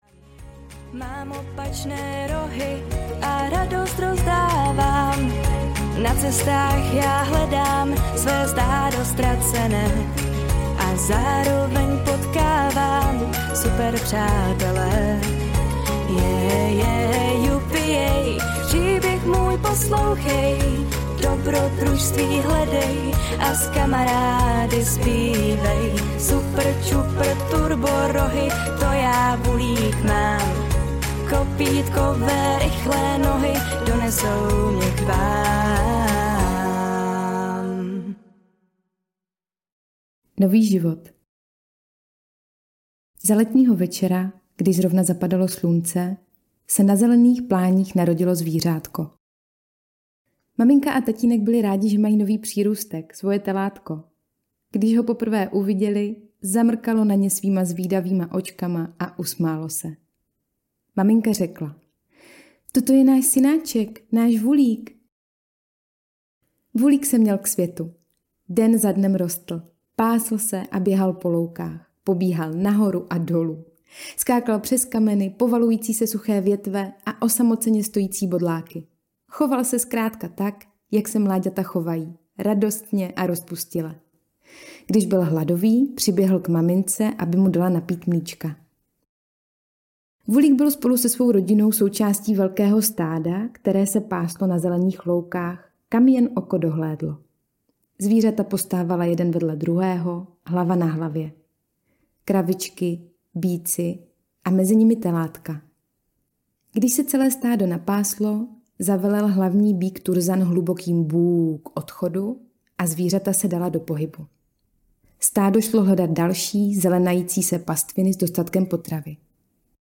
Voolik na cestách audiokniha
Ukázka z knihy